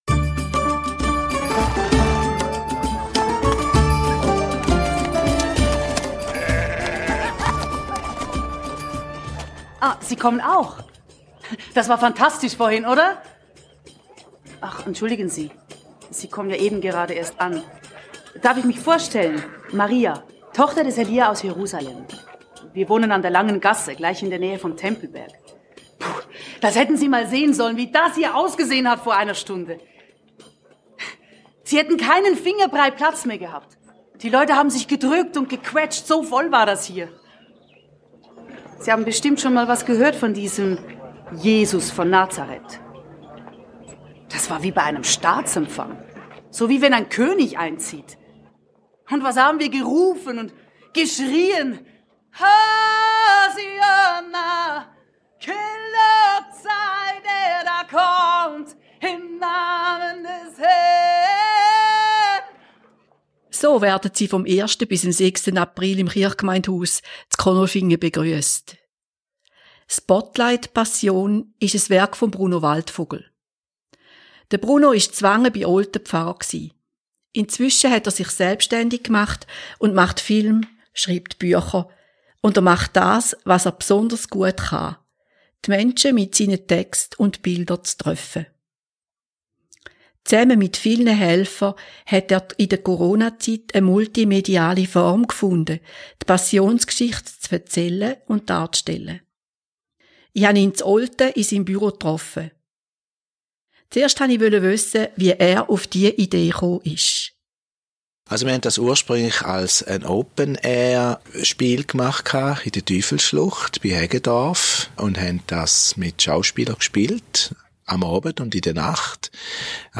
Wer steckt hinter der Installation, welche Gedanken sind den Macher:innen wichtig und welche Ziele verfolgen sie? Das klären wir im Vorfeld des Ereignisses, und wir hören auch Ausschnitte aus der Aufführung.